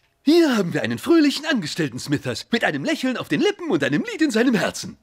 Fröhlicher Angestellter.wav